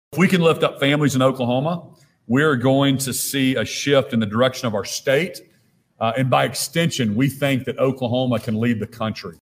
CLICK HERE to listen to commentary from Governor Kevin Stitt.